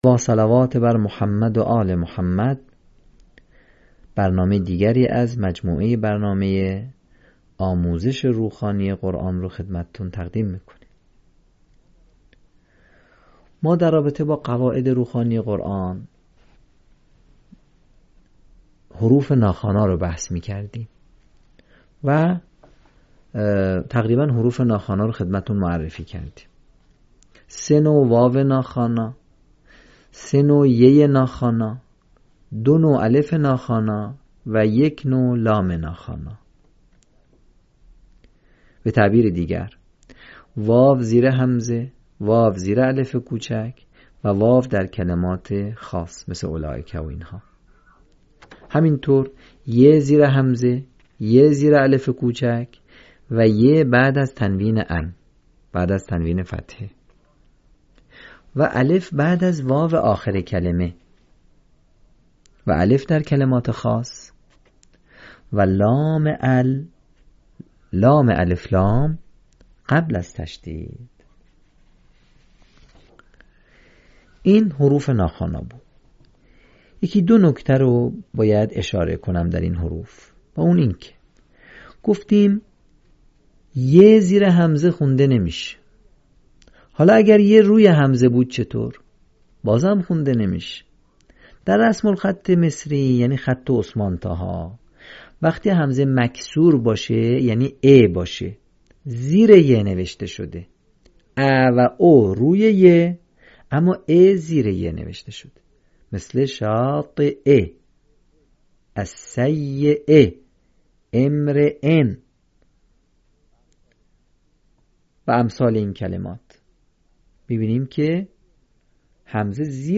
صوت | آموزش روخوانی «ی ناخوانا»